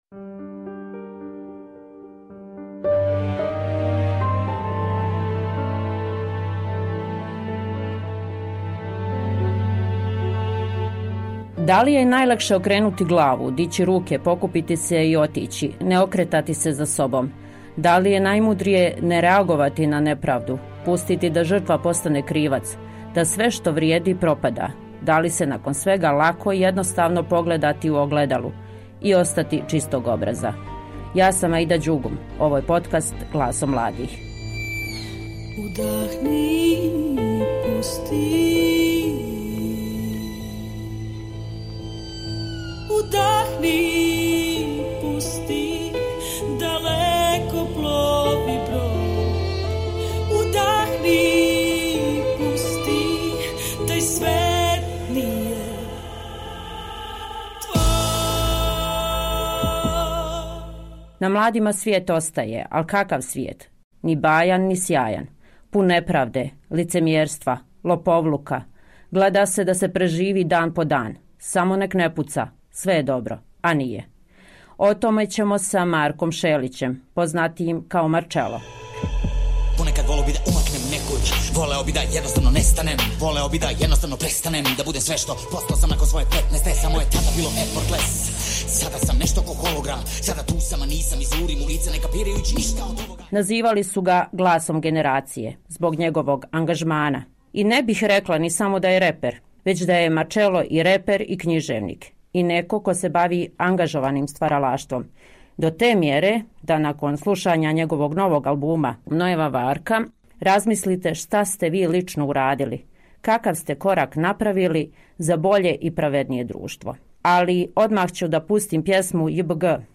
U novoj epizodi Glasom mladih razgovaramo sa Markom Šelićem Marčelom o novom albumu “Nojeva varka”, radu na Perspektivi, apatiji i uticaju muzike na razmišljanje mladih.